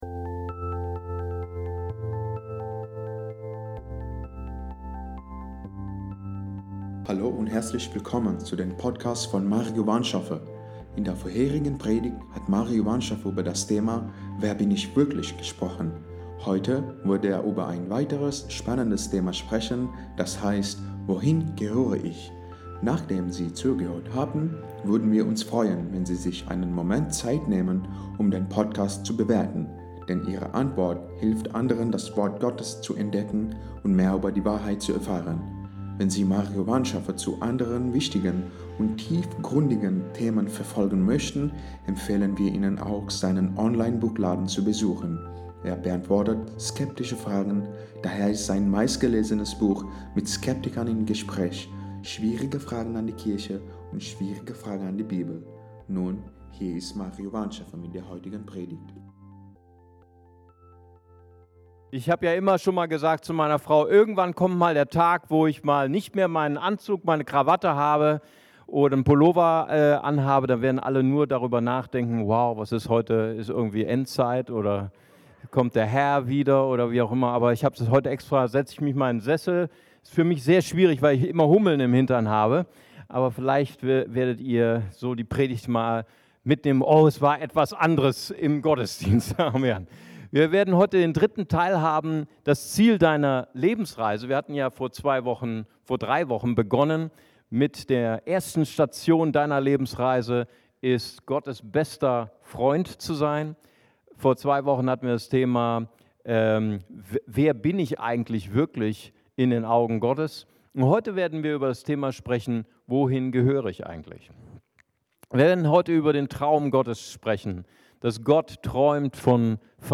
Diese Predigtreihe nimmt Dich auf eine spannende Reise zu der Bestimmung Deines Lebens!